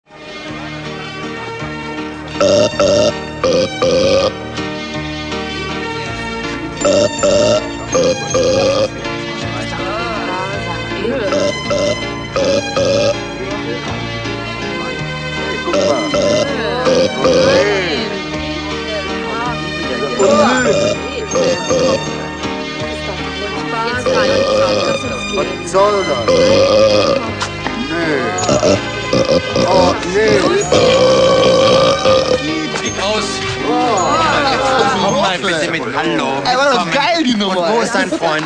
ruelpskonzert.mp3